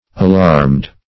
Alarmed \A*larmed"\, a.